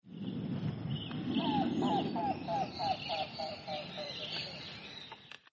Cuclillo Canela (Coccyzus melacoryphus)
Audio_CuclilloCanela_RECS_23Nov2024.mp3
Nombre en inglés: Dark-billed Cuckoo
Localidad o área protegida: Reserva Ecológica Costanera Sur (RECS)
Condición: Silvestre
Certeza: Vocalización Grabada